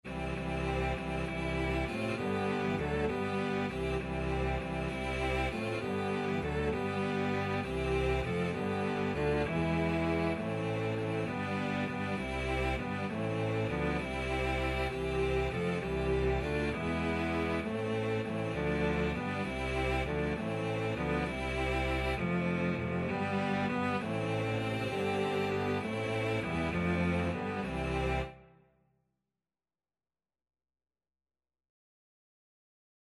In Dulci Jubilo Free Sheet music for Cello Quartet
Info: In dulci jubilo ("In sweet rejoicing") is a traditional Christmas carol.